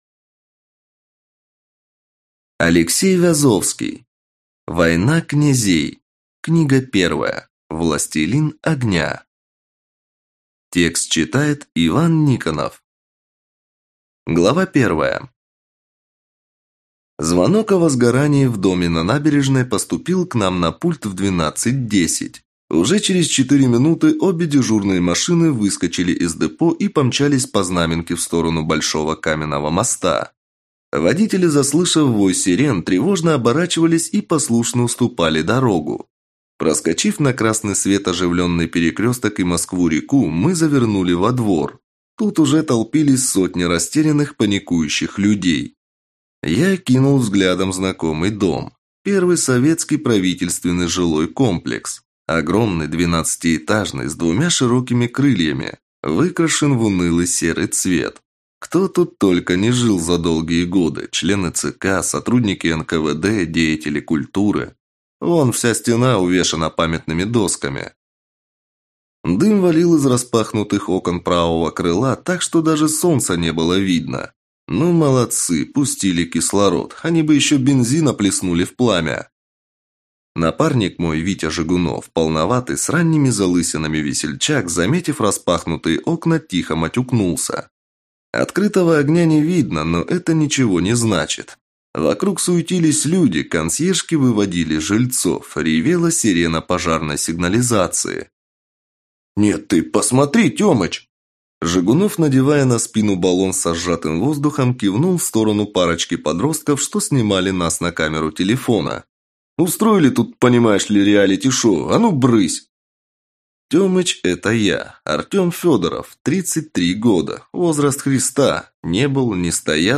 Аудиокнига Война князей. Властелин Огня | Библиотека аудиокниг